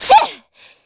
Ripine-Vox_Attack6_kr.wav